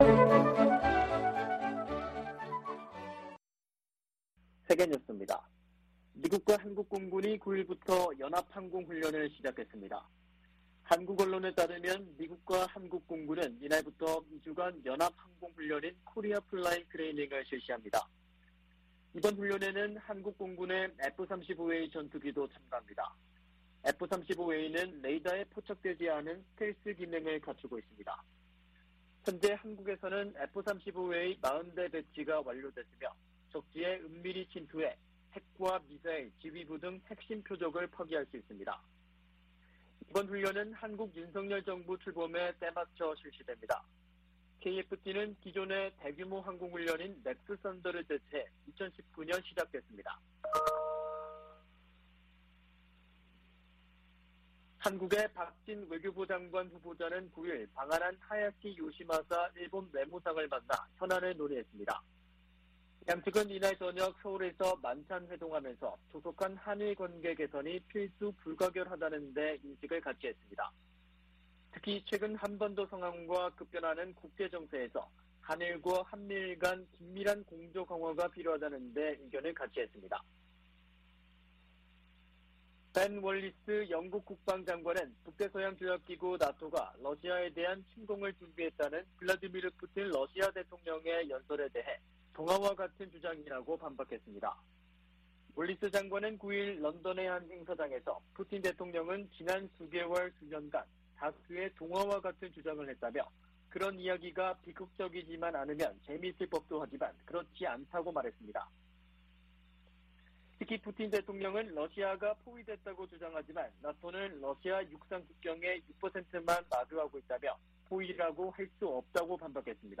VOA 한국어 아침 뉴스 프로그램 '워싱턴 뉴스 광장' 2022년 5월 10일 방송입니다. 북한이 7일 오후 함경남도 신포 해상에서 잠수함발사 탄도미사일(SLBM)을 발사했습니다. 미 국무부는 미사일 도발을 이어가는 북한을 규탄하고 한・일 양국에 대한 방어 공약을 재확인했습니다. 미 하원에서 오는 12일 올해 첫 대북 정책 청문회가 개최될 예정입니다.